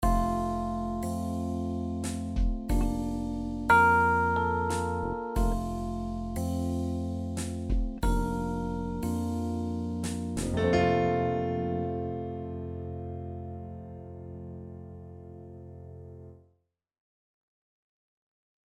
Relaxed, cocktail music Mood
Piano, strings Genre: Easy listening Composer/Artist
Plays long start of the track